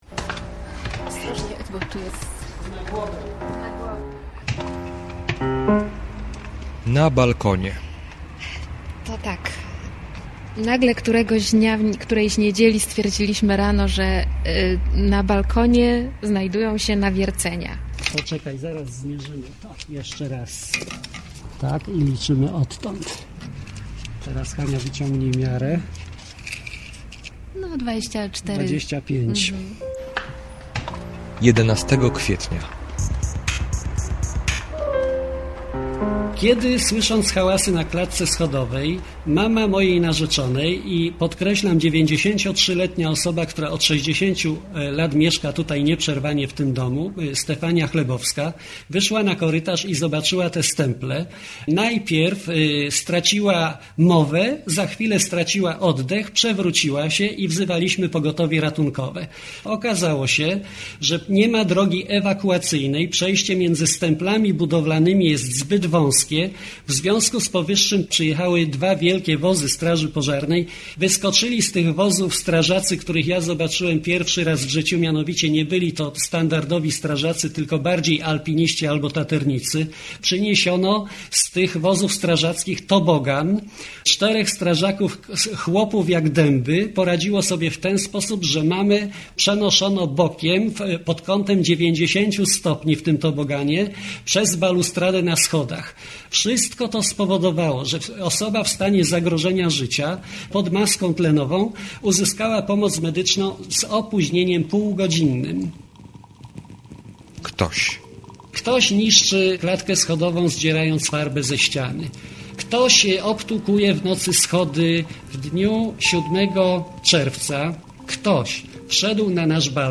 - reportaż